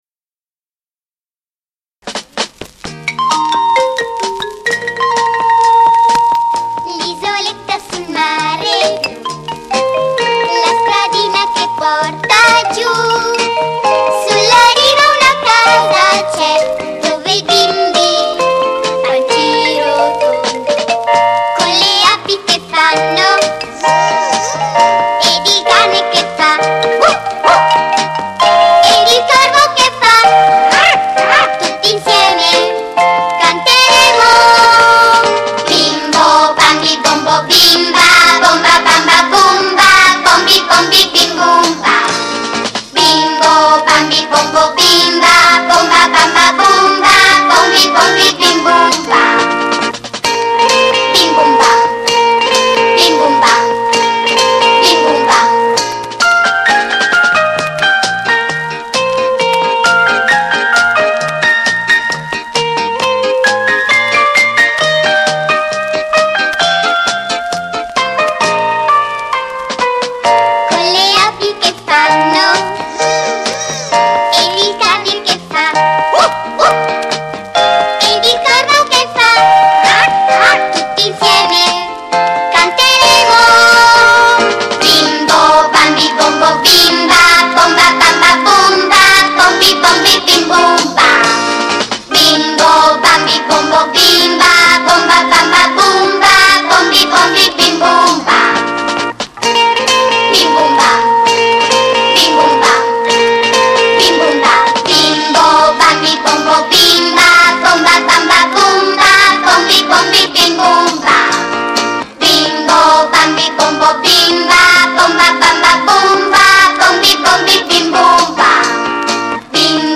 CORO E ORCHESTRA